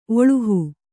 ♪ oḷuhu